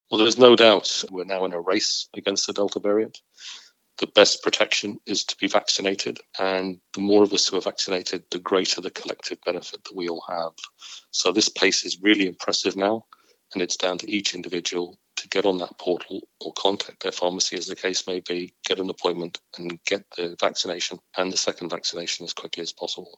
Former head of the HSE, Tony O’Brien, says the programme needs to continue at pace, due to the rise in Covid cases.